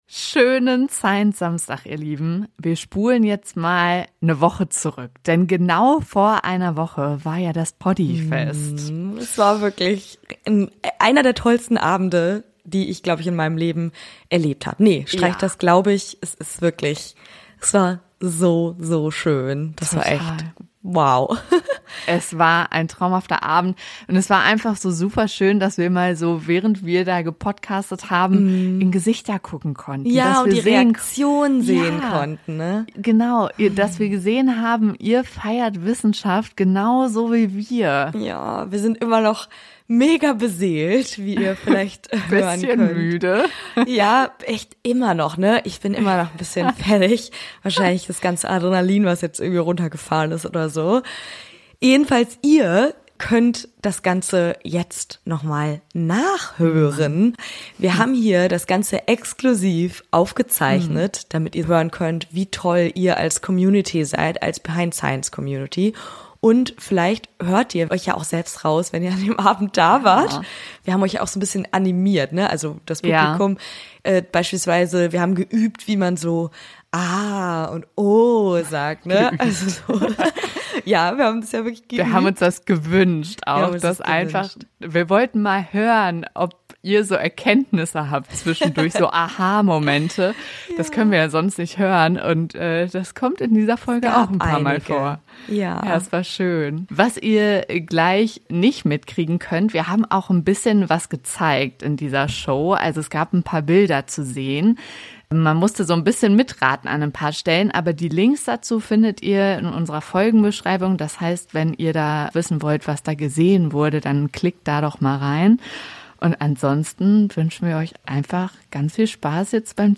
Zu unserer ersten Podcast-LIVE-Folge haben wir uns einen extravaganten Wissenschaftler rausgepickt: Leonardo da Vinci.